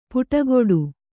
♪ puṭagoḍu